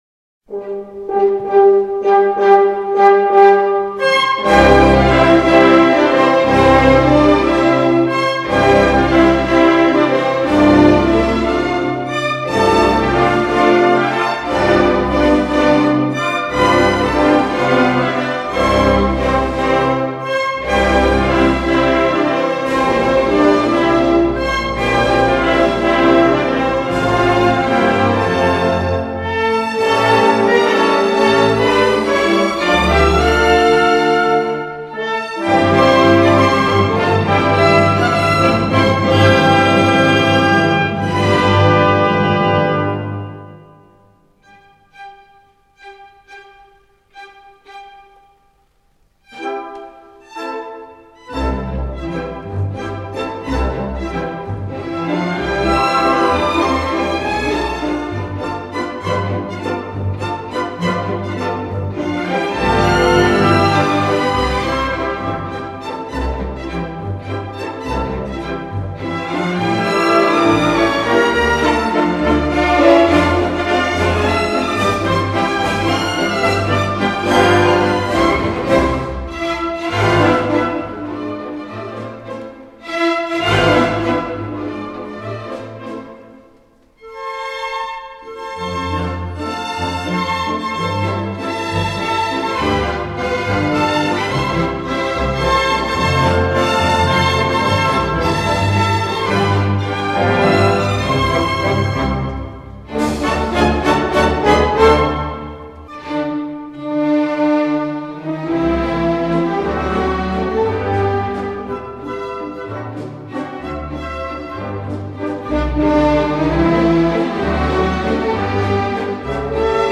Treasure-Waltz.wav